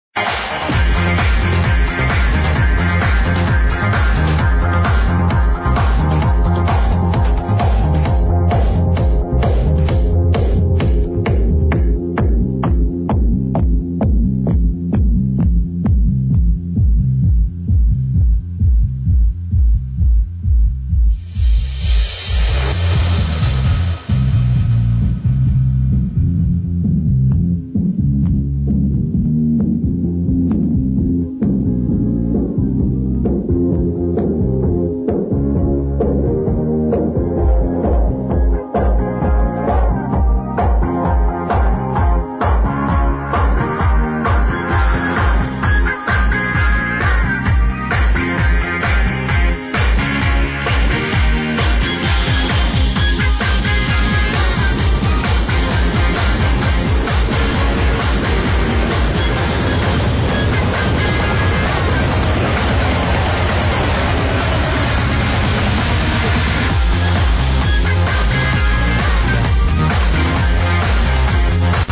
ID this easy house tune from last year please